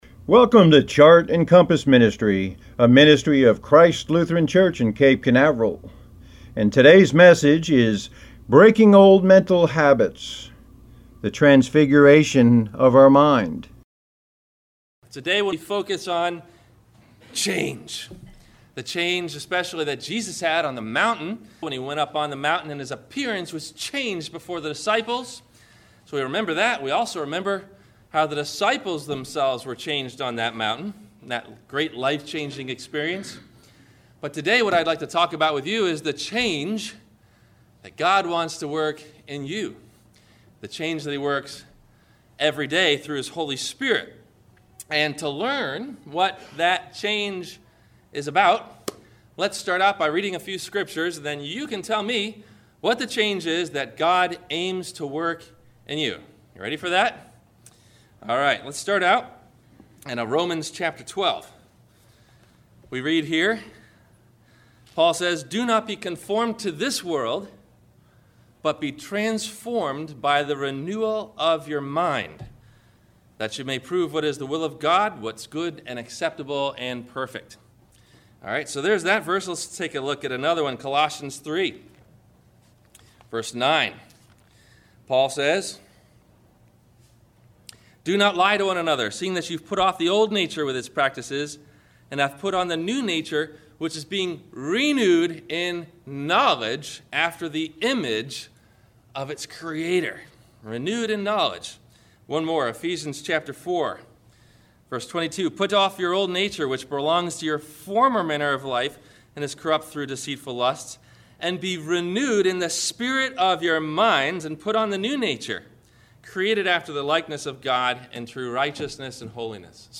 Breaking Old Mental Habits : The Transfiguration of our Mind – WMIE Radio Sermon – June 13 2016